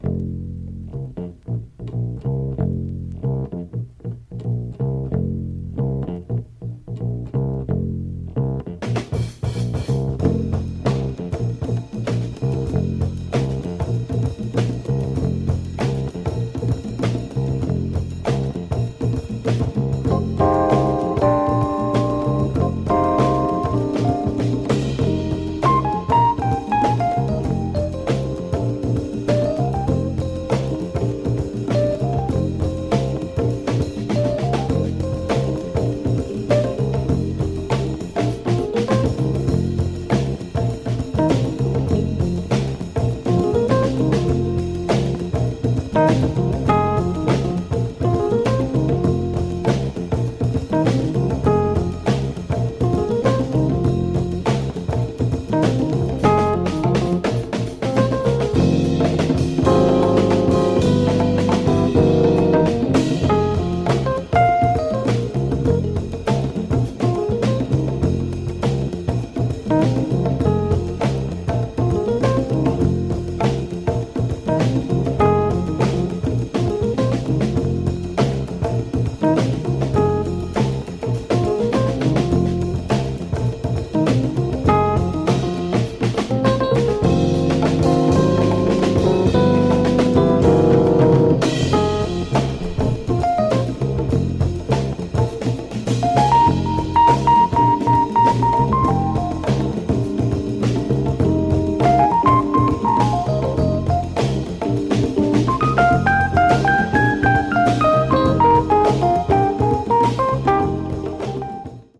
Creole jazz masterpiece !
Caribbean , Jazz